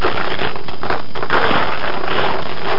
Amiga 8-bit Sampled Voice
shuffle.mp3